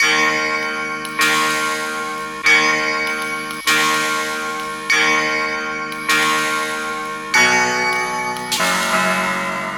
Rock Star - Piano Express.wav